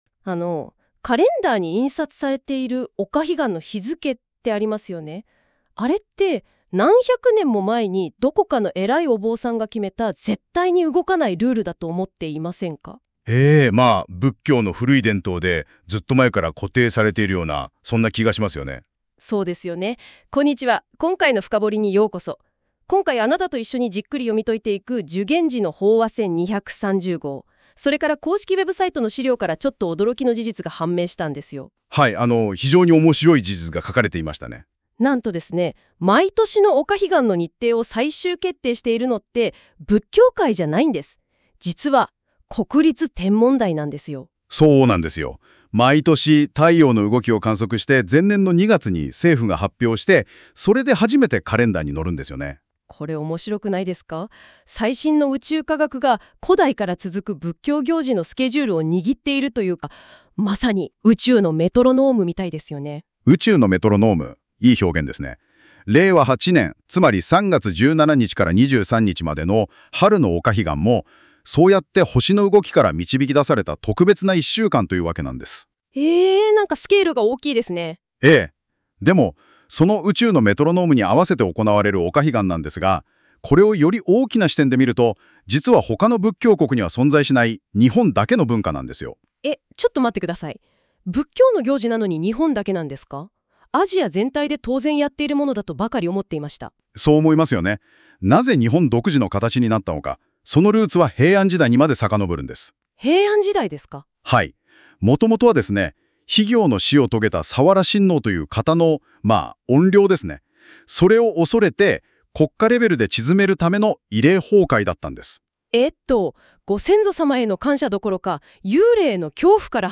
＊この法話箋は音声ラジオ（Audio Overview）で解説しています（尺：6分23秒）。
注：音声はAIによる自動生成のため、一部読み方に間違いがあります。